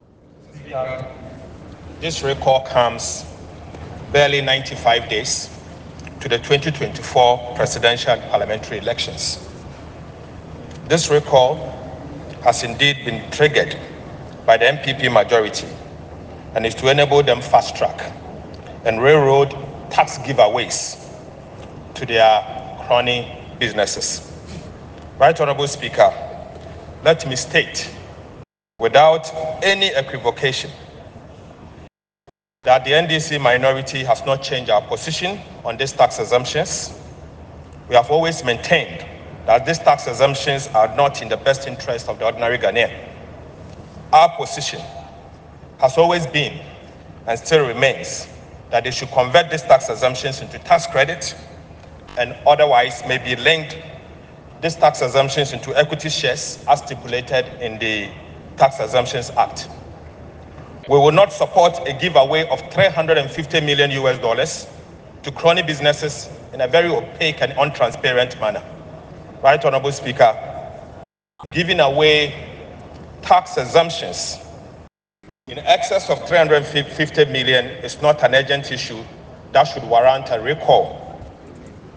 Addressing the floor on September 3, Dr. Ato Forson emphasized that National Democratic Congress (NDC) MPs oppose these tax exemptions, contending that they are not in the best interest of ordinary Ghanaians.